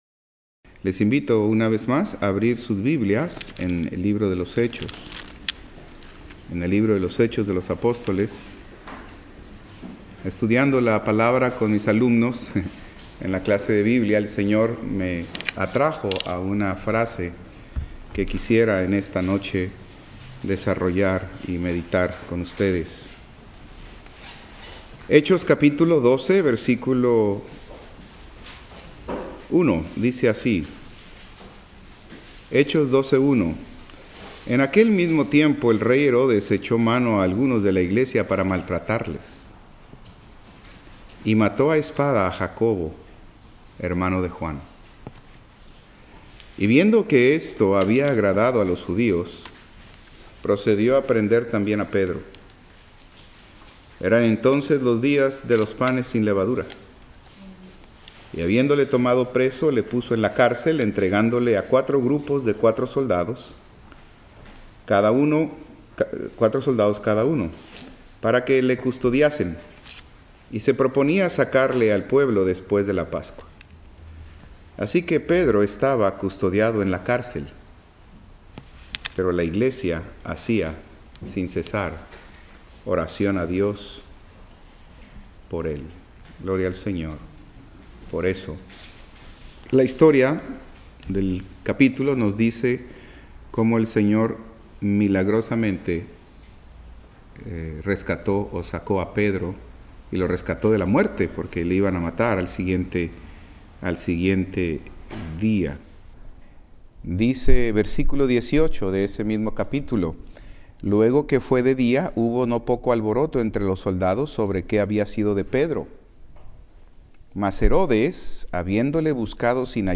Servicio Miércoles